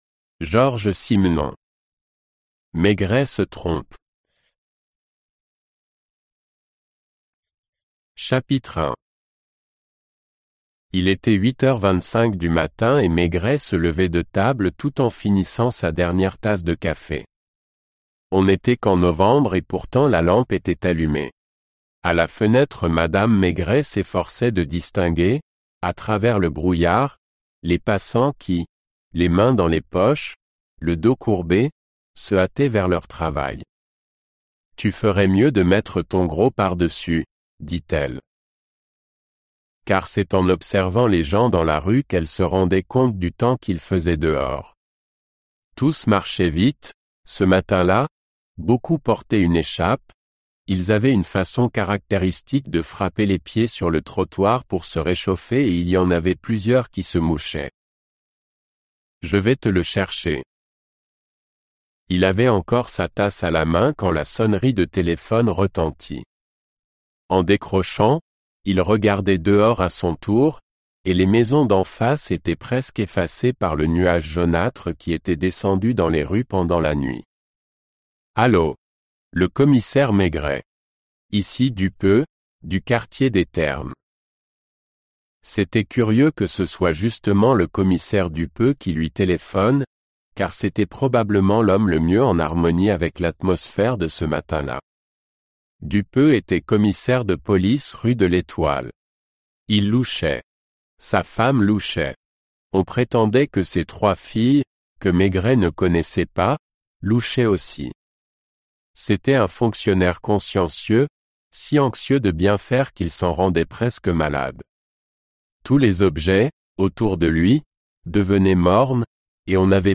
Аудиокнига Ошибка Мегрэ из серии Комиссар Мегрэ - Скачать книгу, слушать онлайн